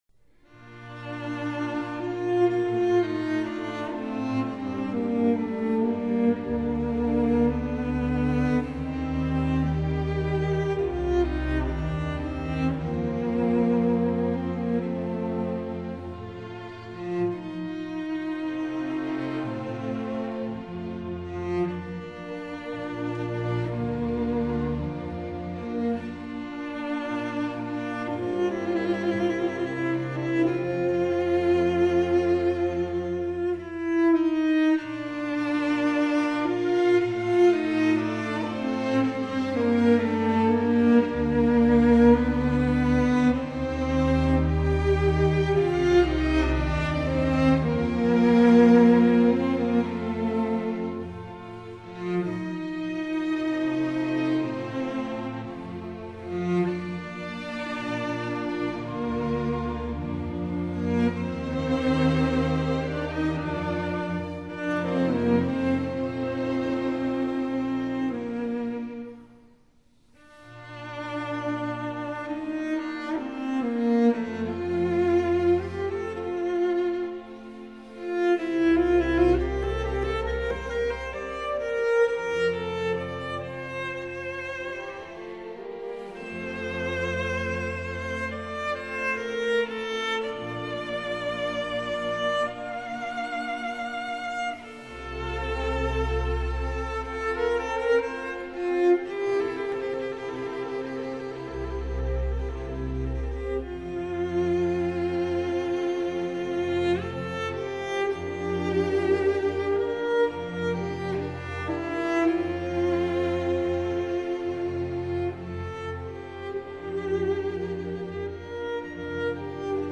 Andantino    [0:05:41.02]